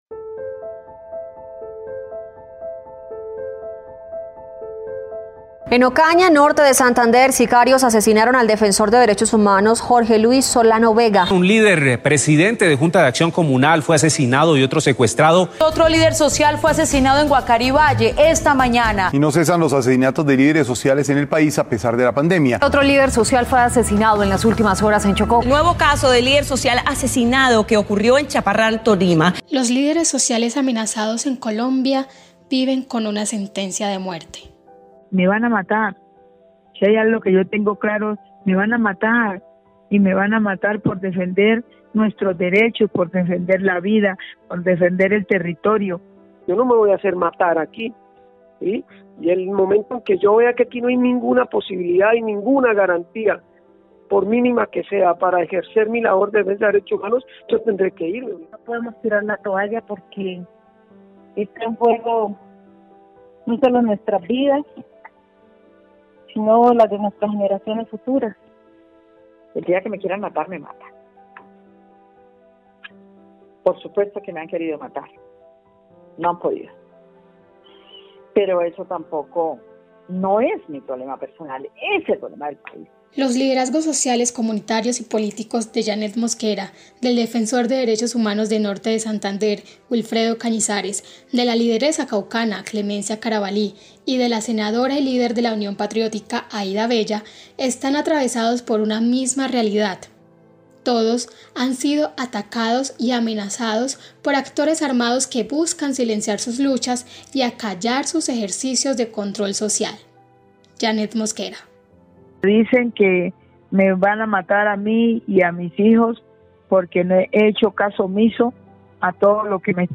Reportaje radial Descargar Cargando...